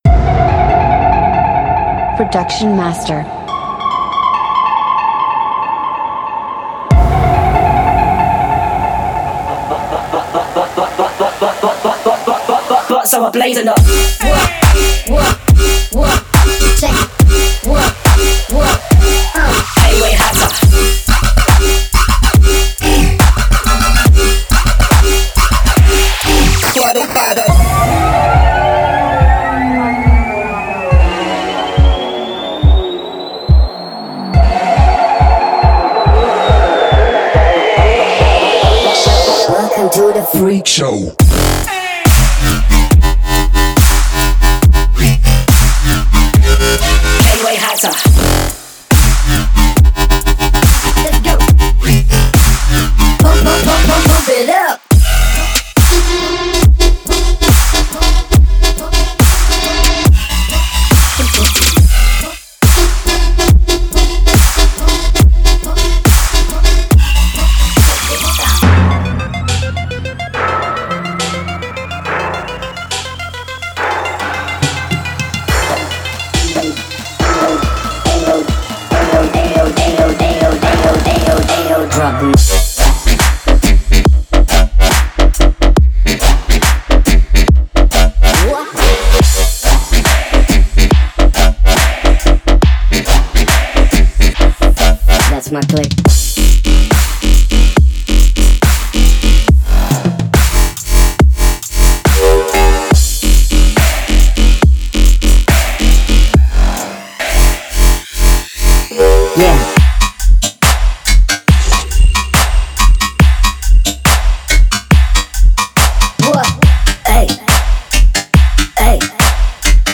DubstepRiddimDubstep
通过使用磨碎的SFX和险恶的气氛，使事情听起来真是多肉。